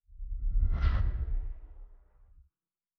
Distant Ship Pass By 7_2.wav